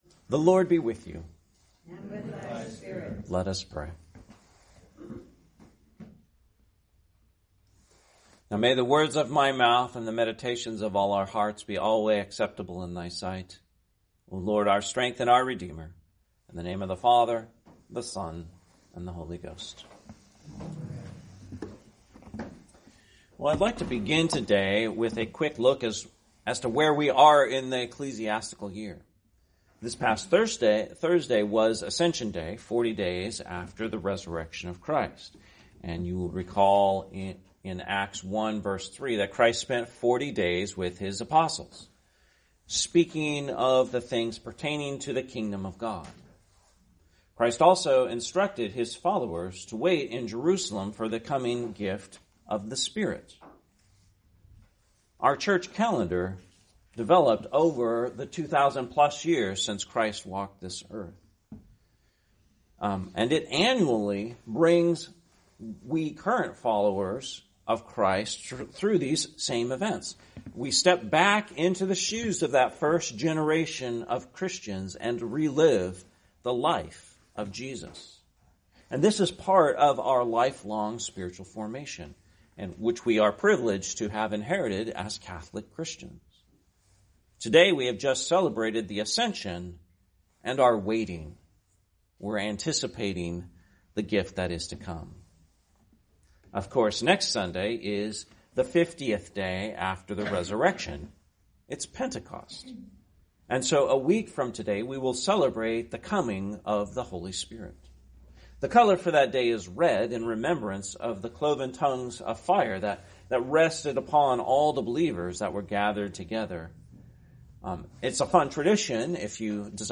Holy Communion Service preceded by lessons and canticles from Morning Prayer